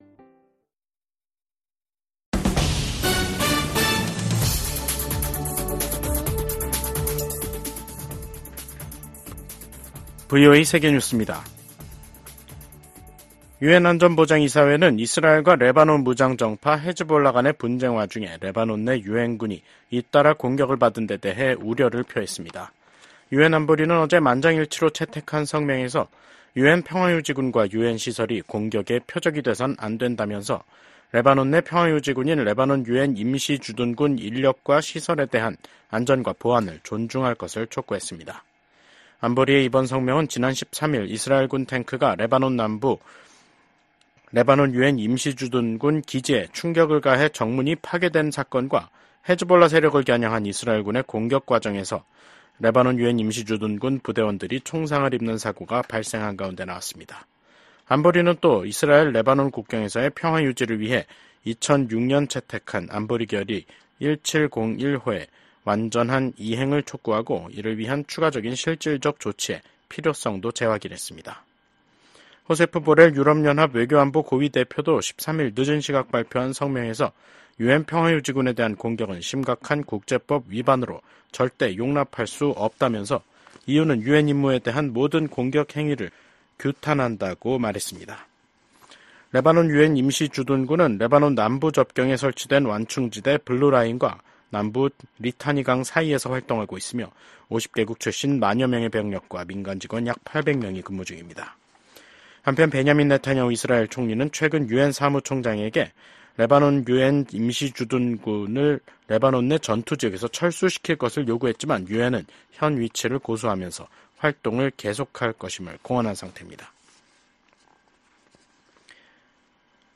VOA 한국어 간판 뉴스 프로그램 '뉴스 투데이', 2024년 10월 15일 2부 방송입니다. 북한의 ‘한국 무인기 평양 침투’ 주장으로 한반도 긴장이 고조되고 있는 가운데 북한군이 오늘(15일) 남북을 잇는 도로들을 폭파했습니다. 16일 서울에서 미한일 외교차관협의회가 열립니다.